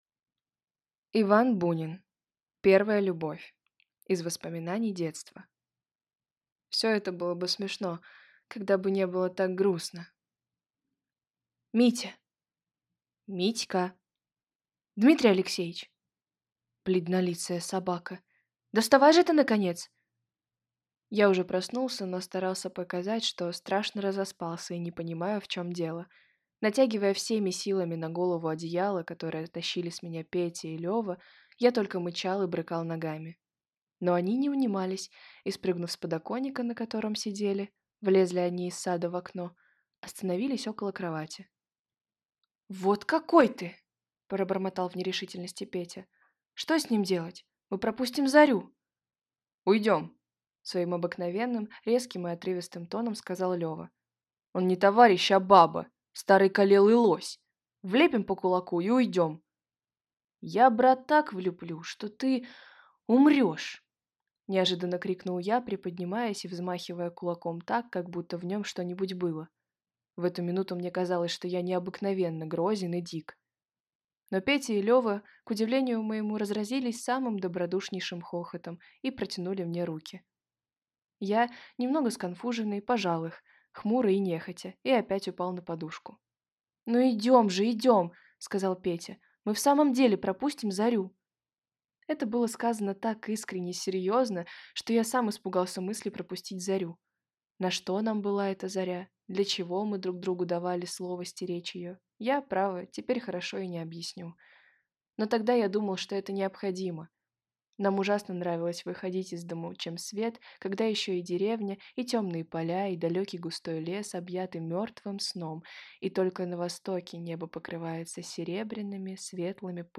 Аудиокнига Первая любовь | Библиотека аудиокниг